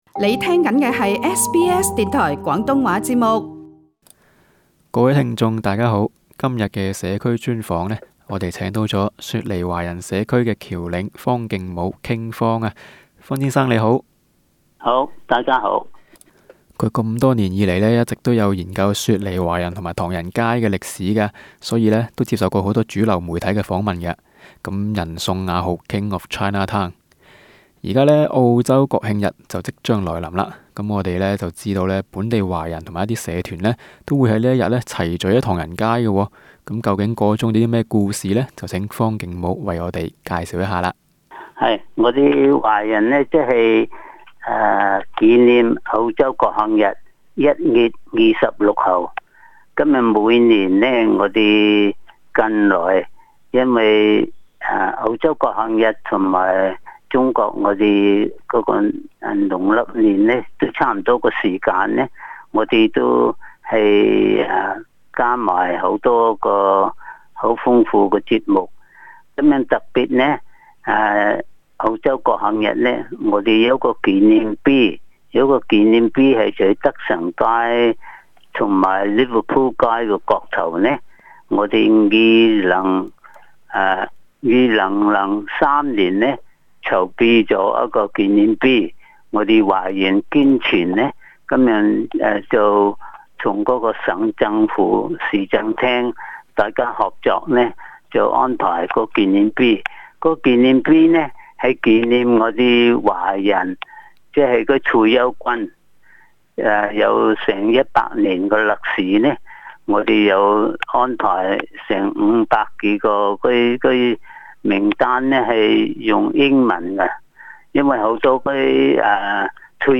【社區專訪】keep FIT 定係 keep FAT 完全由你揸曬FIT